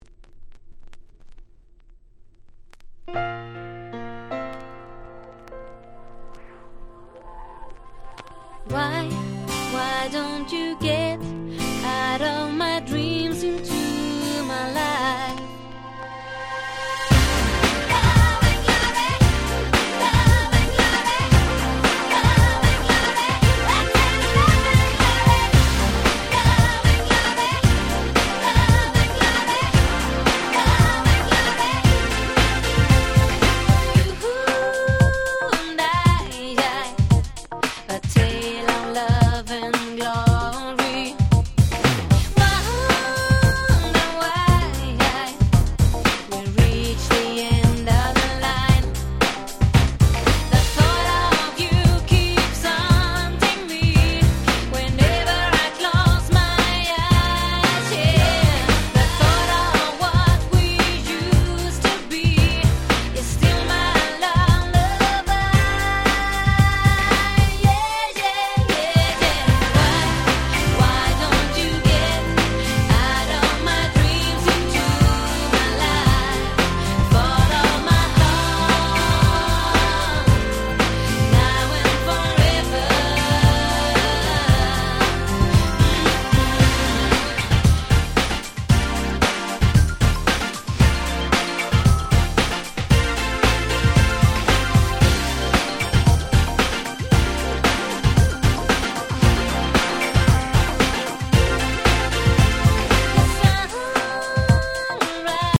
この辺のRagga Pop物、キャッチーでやっぱり最高ですよね。